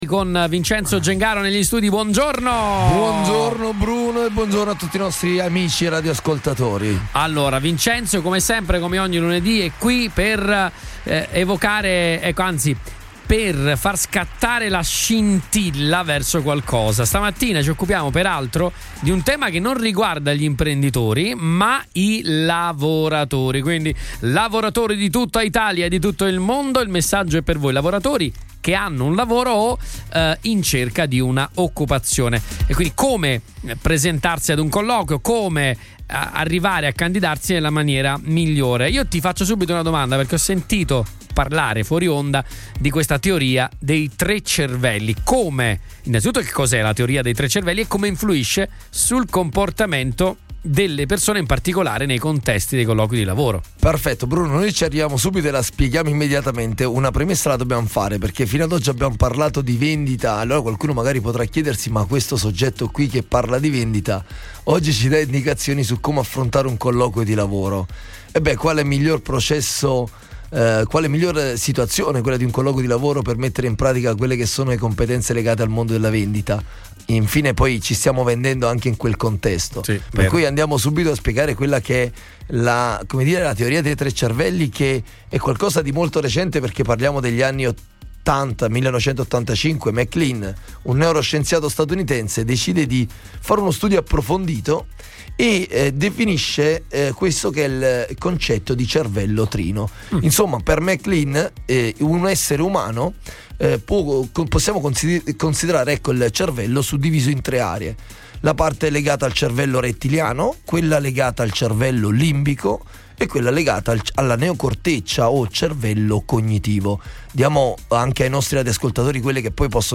consulente vendite e formatore